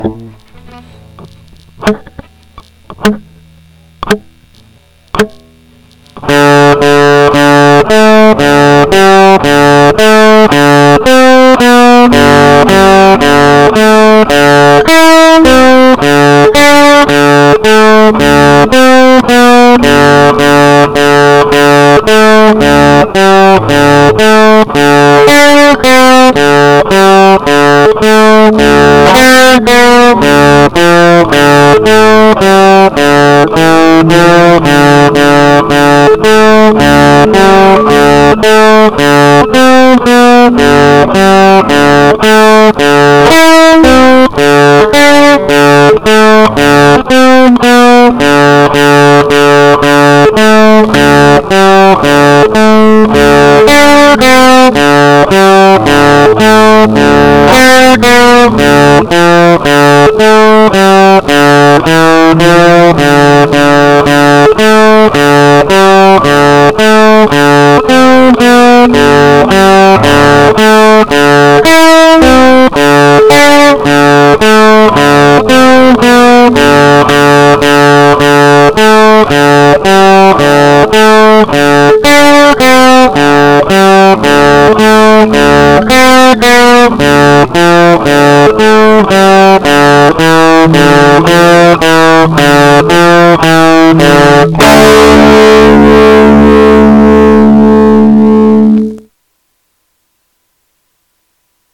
昨日からやってた録音作業がなんとか形になりました。
ものすごくゆ～くりですが（苦笑）。